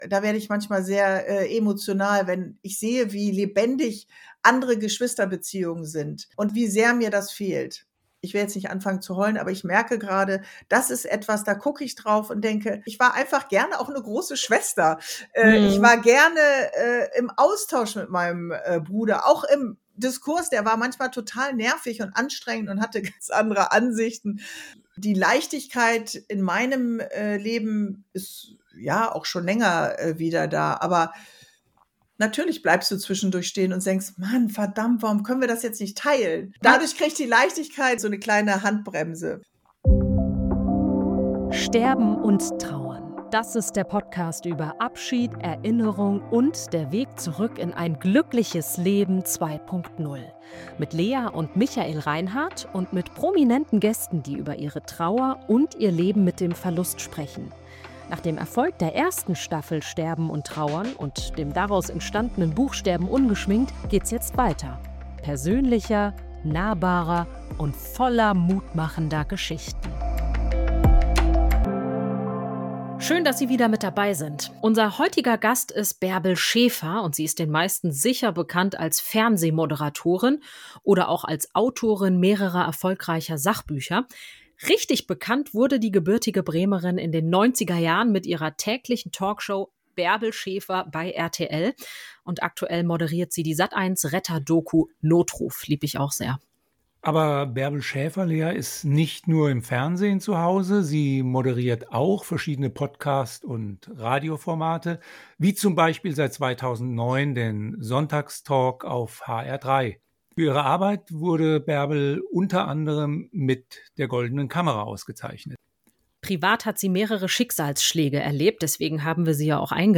Ein intensives, berührendes Gespräch über Abschied, Weitergehen und die Frage: Wie lebt man weiter, wenn das Leben plötzlich stillsteht?